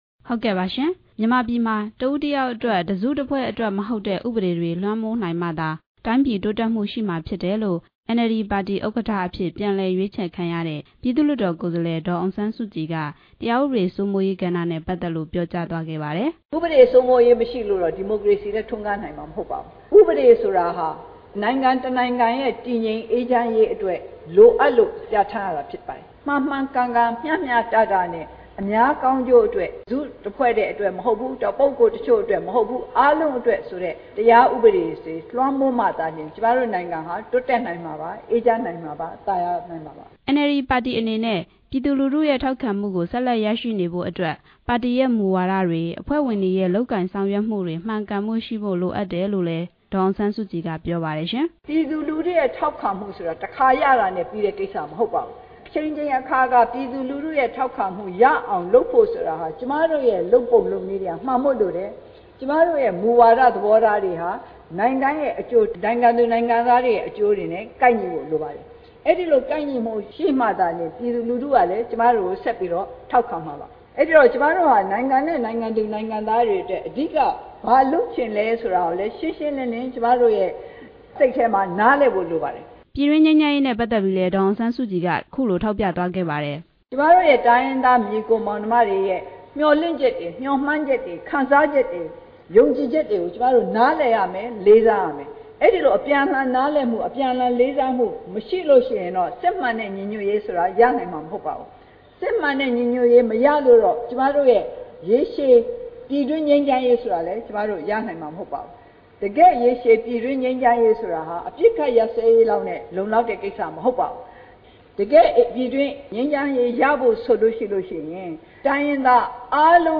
ရန်ကုန်မြို့၊ ဗဟန်းမြို့နယ်၊ တော်ဝင်နှင်းဆီခန်းမမှာ ကျင်းပခဲ့တဲ့ NLD ပါတီ နိုင်ငံလုံးဆိုင်ရာ ညီလာခံ နောက်ဆုံးနေ့ အခမ်းအနား မှာ ဒေါ်အောင်ဆန်းစုကြည်က ပြောကြားခဲ့တာပါ။
NLD အမျိုးသားဒီမိုကရေစီအဖွဲ့ချုပ် ဥက္ကဌအဖြစ် ပြန်လည်တင်မြှောက်ခံရတဲ့ ဒေါ်အောင်ဆန်းစုကြည်က NLD နိုင်ငံလုံးဆိုင်ရာ ဗဟိုညီလာခံနောက်ဆုံးနေ့မှာ မိန့်ခွန်းပြောကြားခဲ့ပါတယ်။